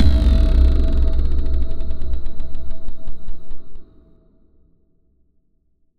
Synth Impact 08.wav